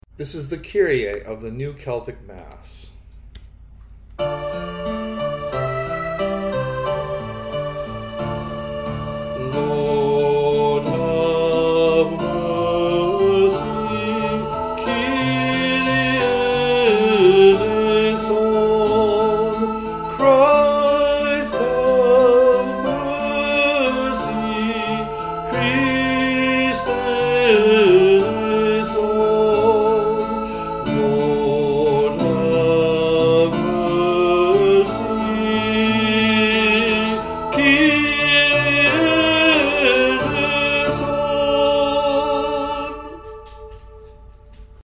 Sunday Worship 9am Music: Celtic Theme
At the 9am service of Holy Eucharist we are singing music from the New Celtic Mass by James Wallace.